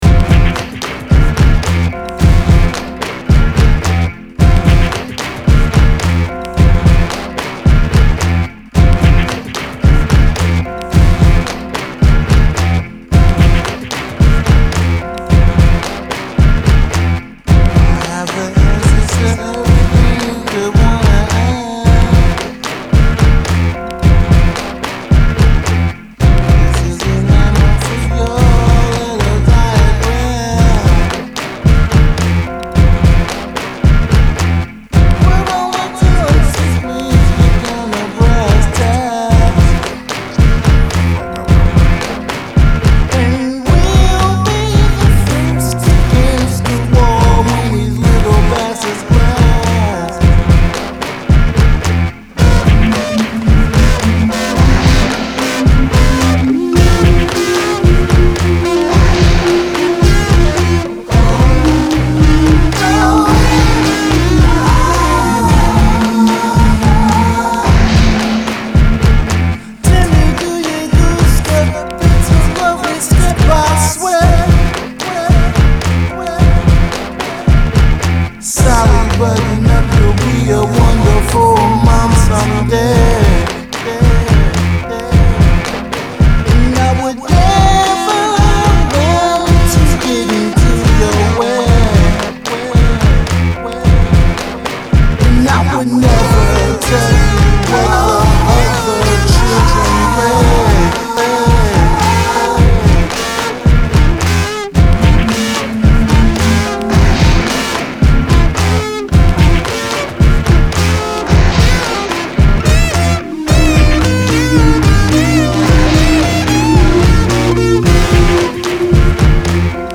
Recorded in 2005
transformative and abrasive